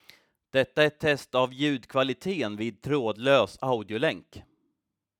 Spektrumet av FM-modulerad röstinspelning såg då ut så här: